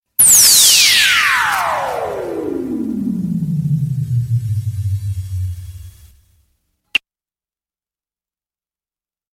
LASERLASER
17-scicoorlaser.mp3